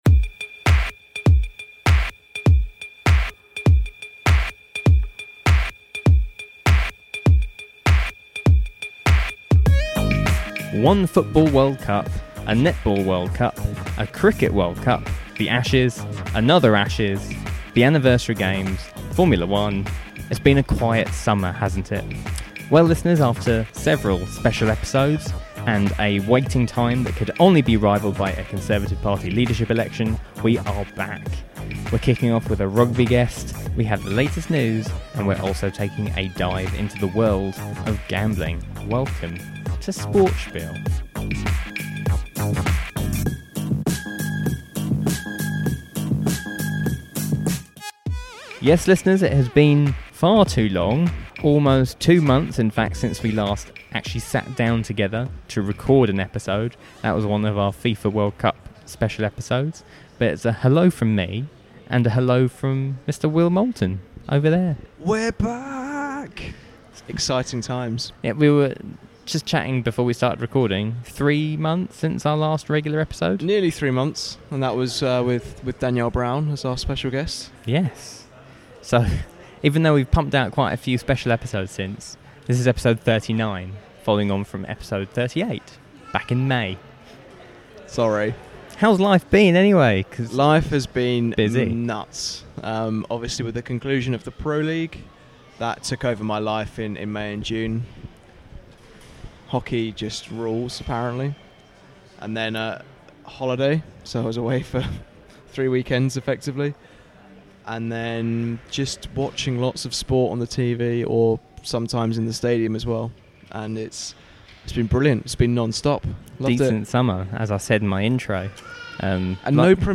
Feature Interview On our feature interview section